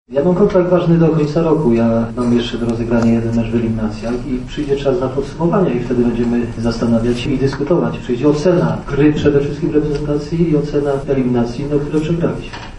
Waldemar-Fornalik-konferencja-o-ewentualnej-dymisji1.mp3